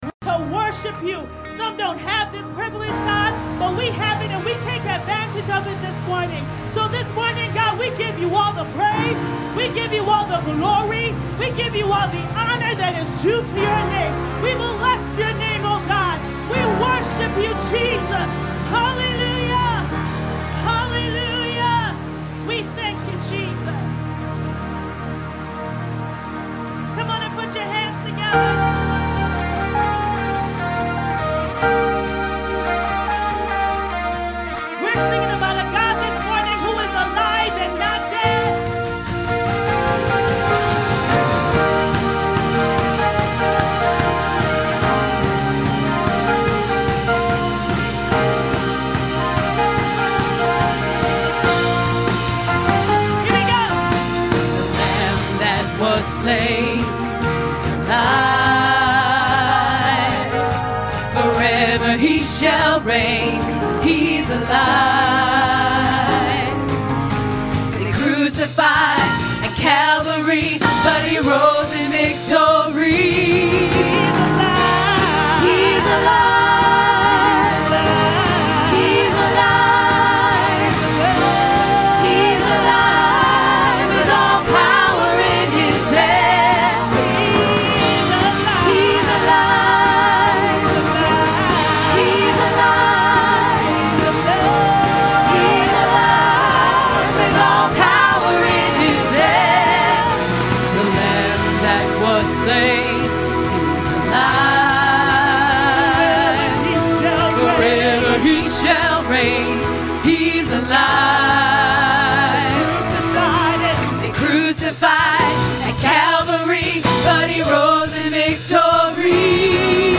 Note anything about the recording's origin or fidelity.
WORDS CARRY OR CRUSH 2ND SERVICE